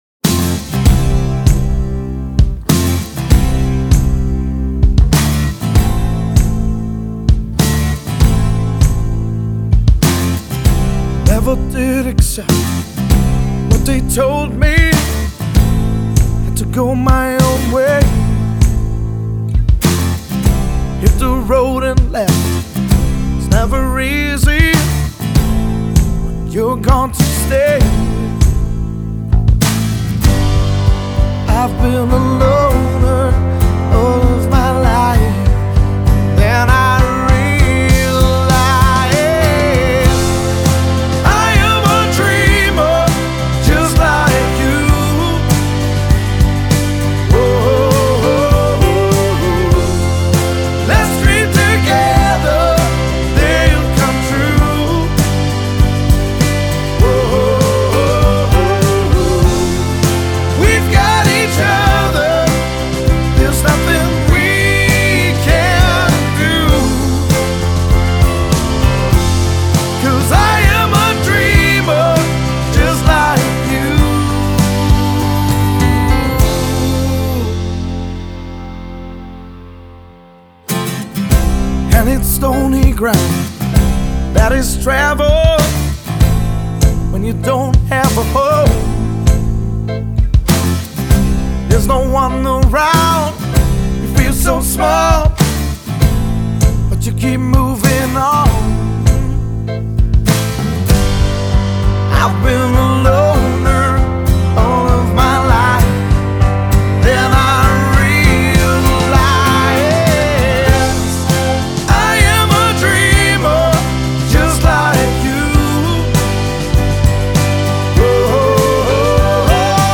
Genre: Pop, Rock, Blues